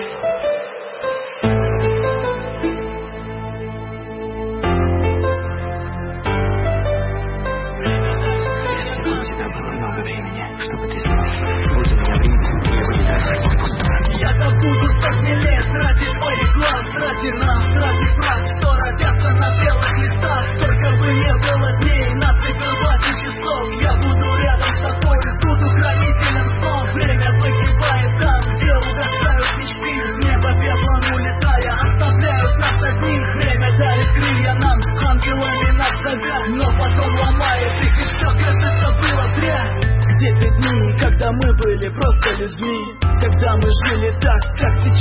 Музыка | Реп
Зона обмена: Музыка | Русский RAP/Hip-hop